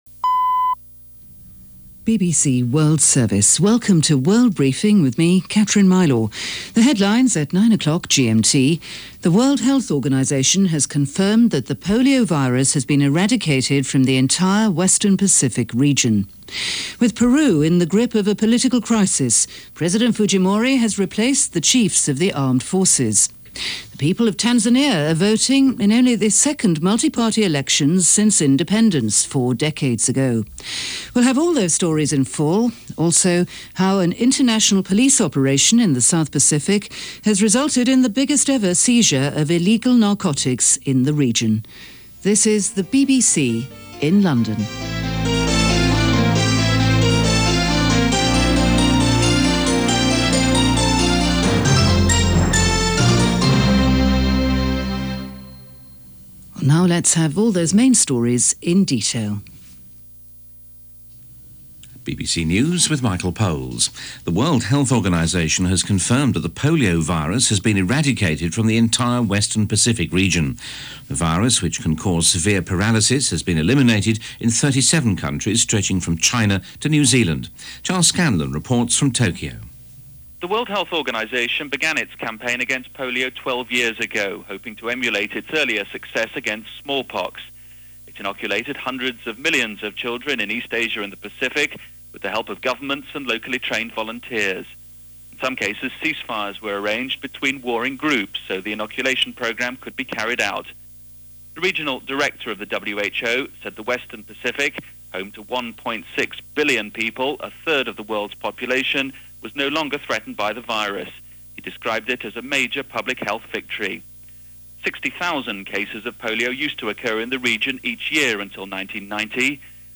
– BBC World Service – World Briefing – October 29, 2000 – Gordon Skene Sound Collection –